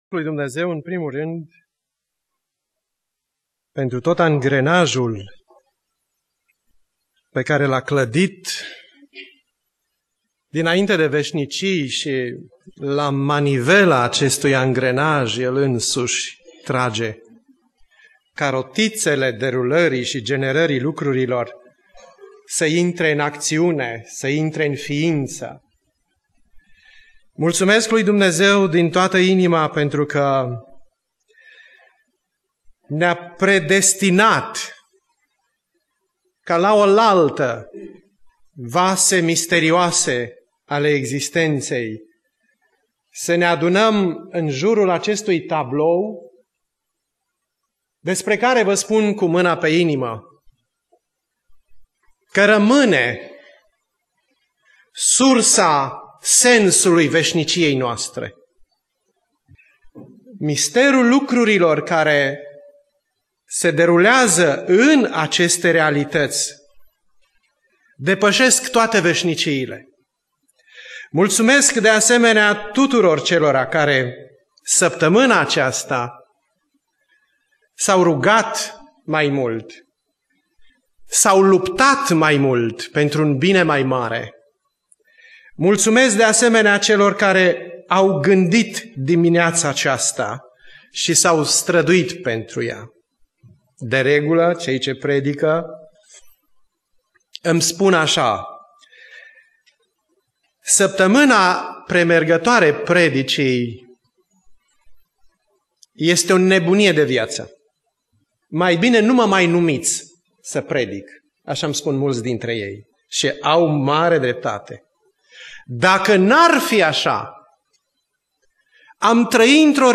Predica Aplicatie - Matei 27